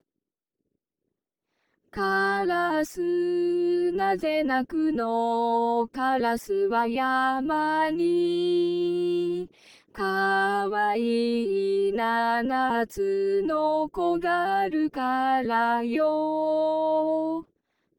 母音音声の無限音階化